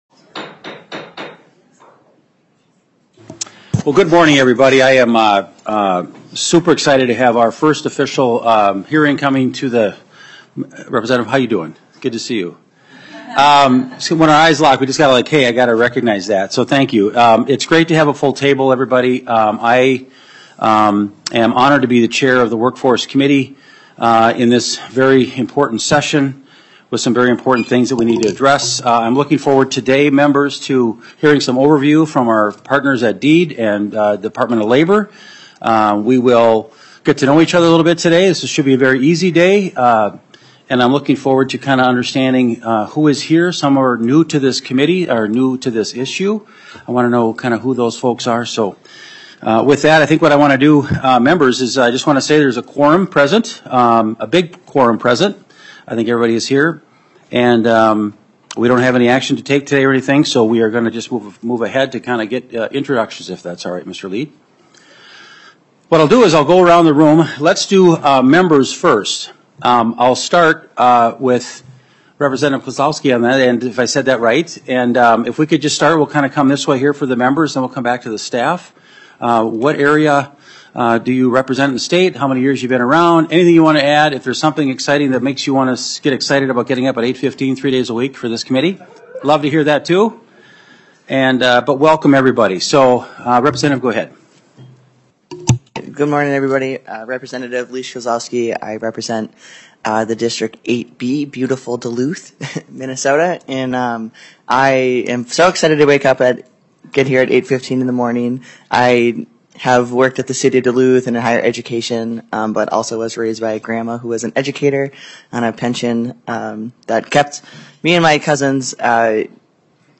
Meeting Details - Tuesday, April 1, 2025, 8:15 AM